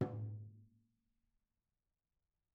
TomH_HitS_v2_rr2_Mid.mp3